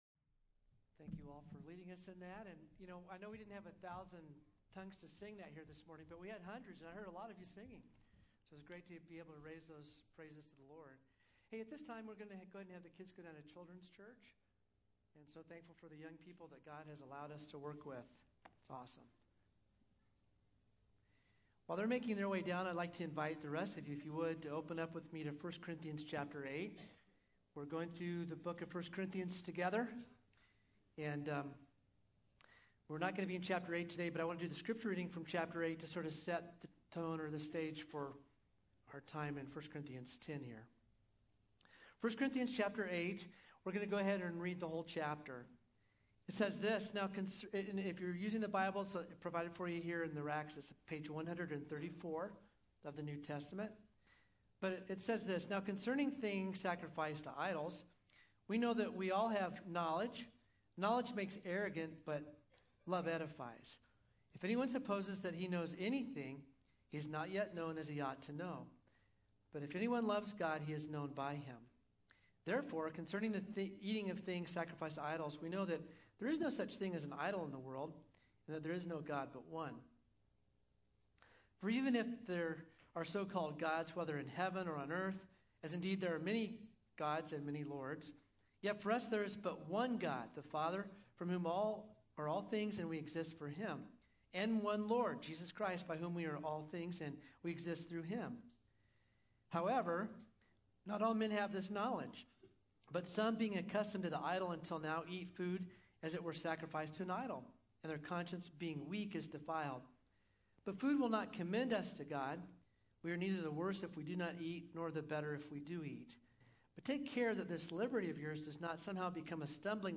5-28-23-sermon.mp3